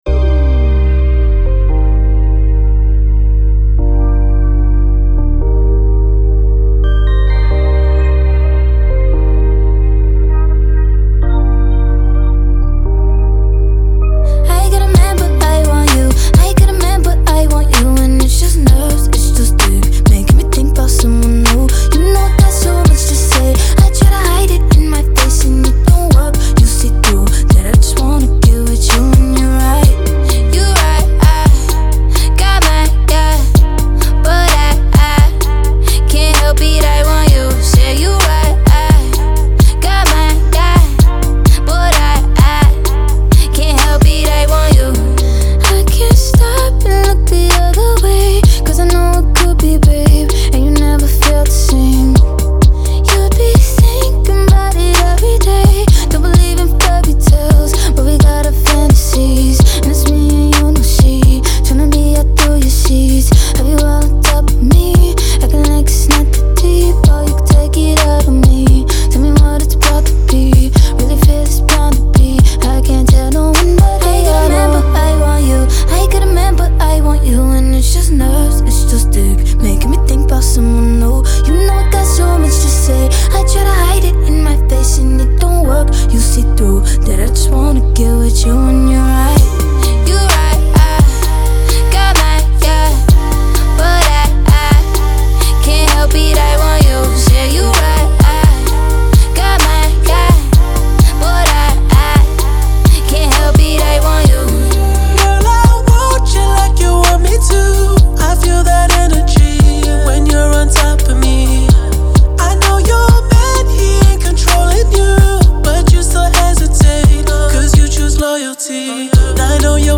Genre : Pop, R&B